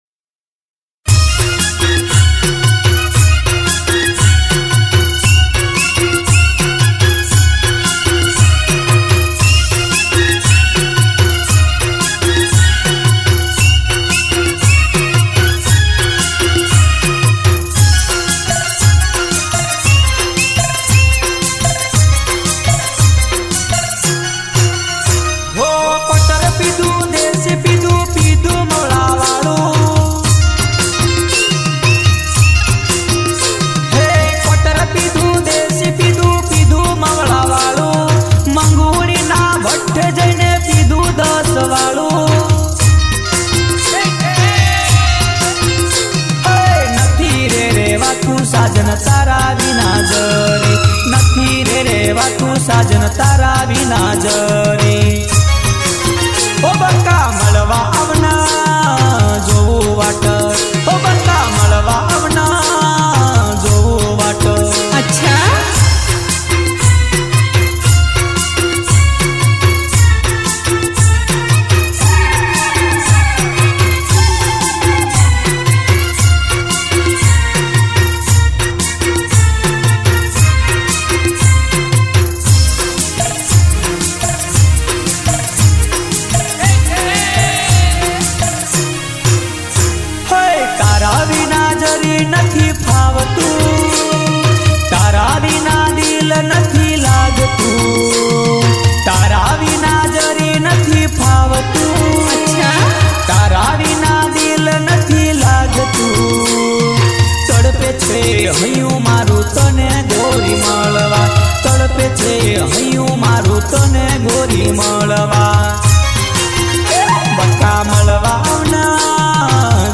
Gujarati DJ Mp3 Songs